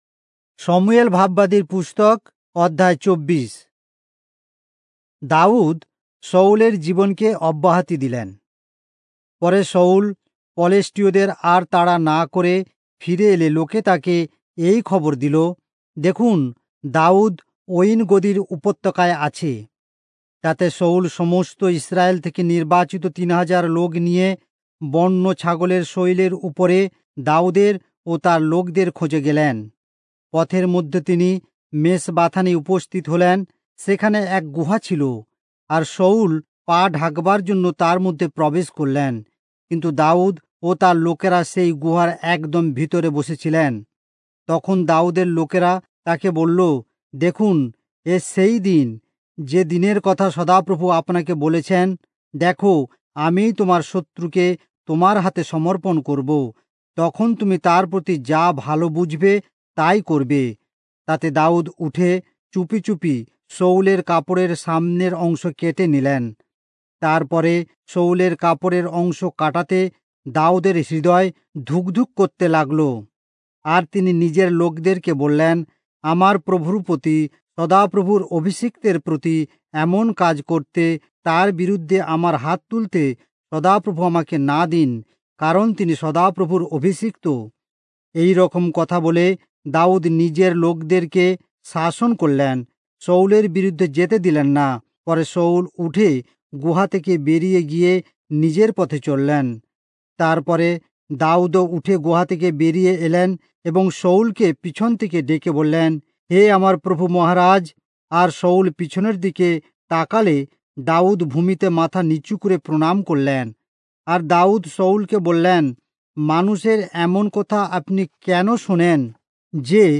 Bengali Audio Bible - 1-Samuel 3 in Irvbn bible version